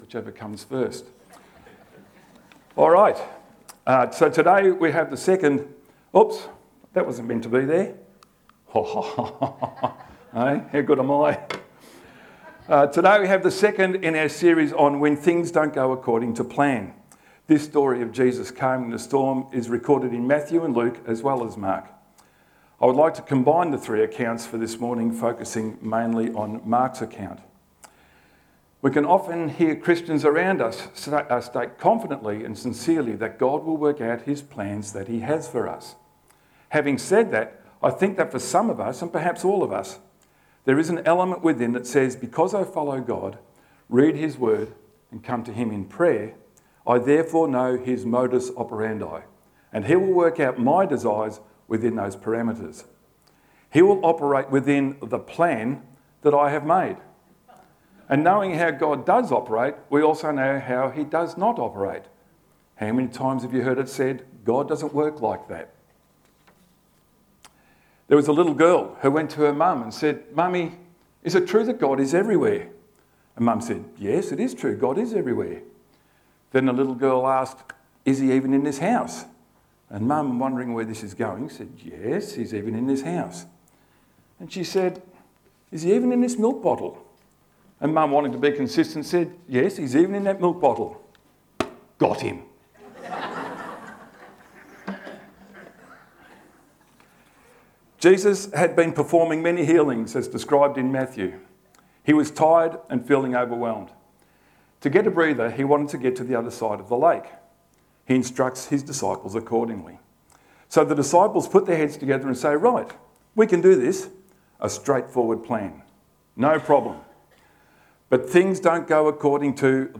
Lilydale Baptist Church 12th January 2025 10am Service